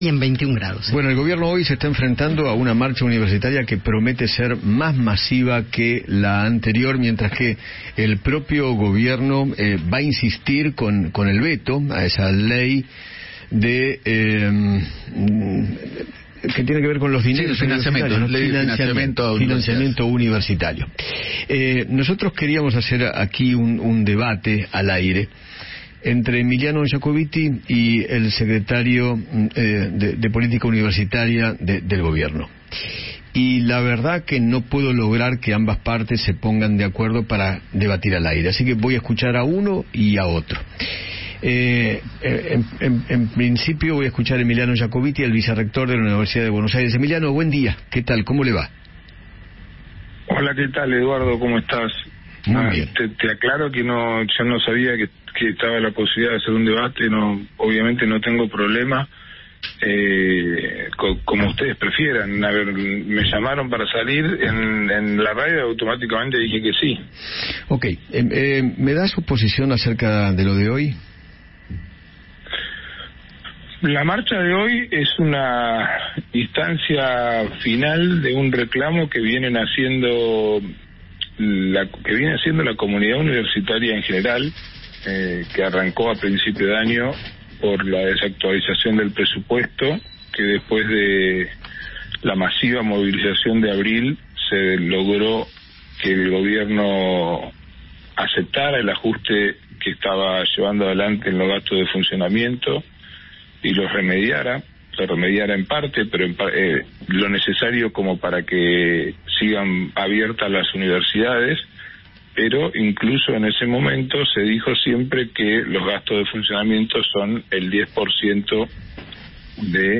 En la previa de la marcha universitaria, el vicerrector de la UBA, Emiliano Yacobitti, conversó con Eduardo Feinmann sobre el presente educativo y las idas y vueltas con el Gobierno por el presupuesto universitario.